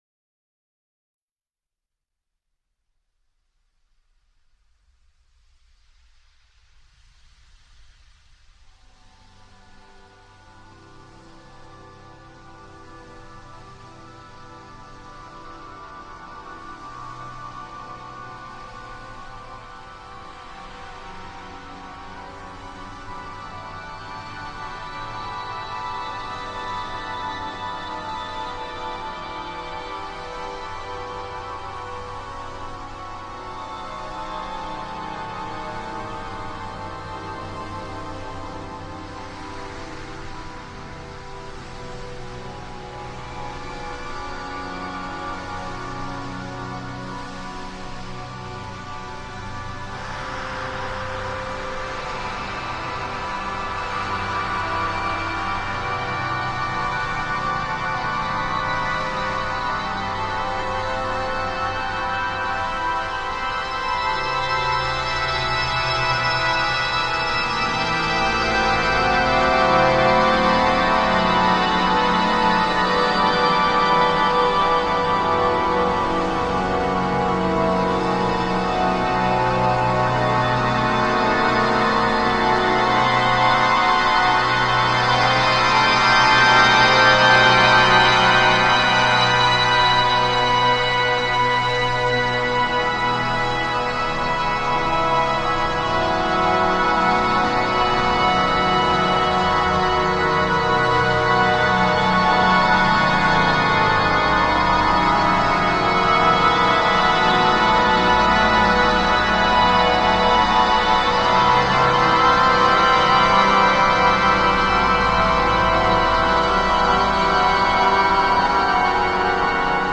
It is comparised of various organ music pieces composed by Bach.
Organ Record.mp3